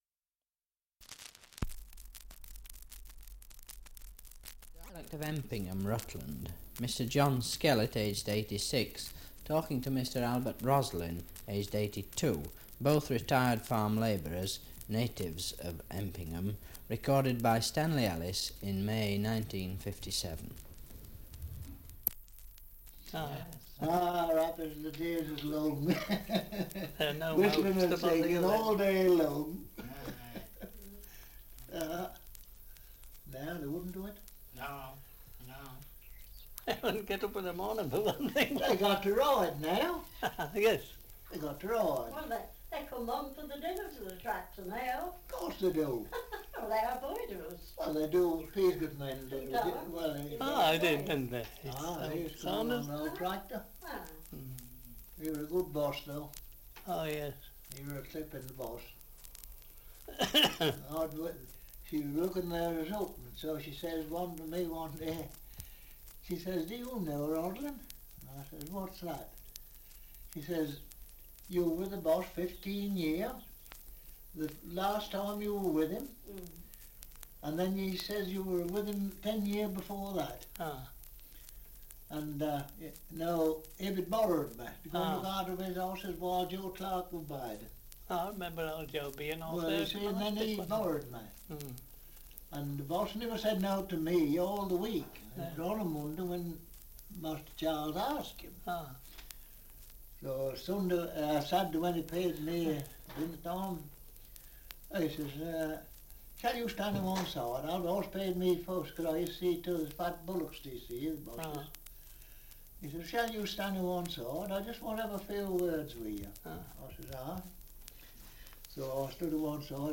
Survey of English Dialects recording in Empingham, Rutland
78 r.p.m., cellulose nitrate on aluminium